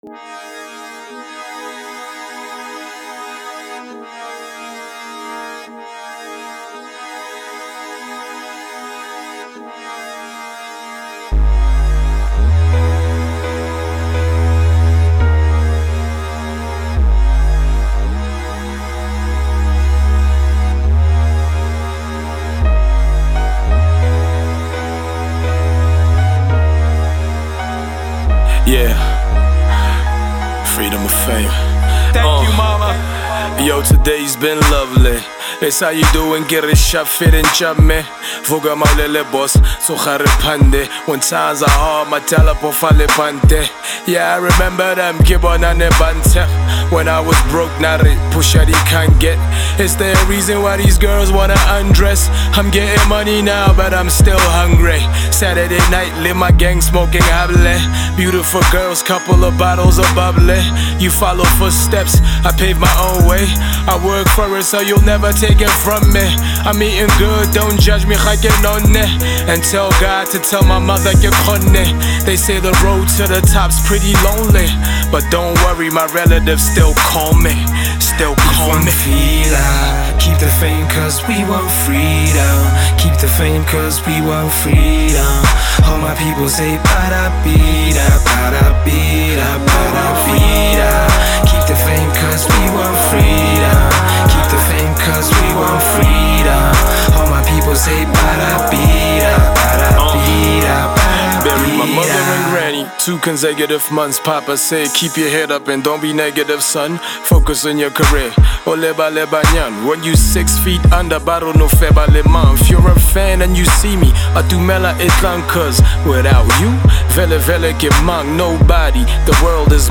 Sa hip hop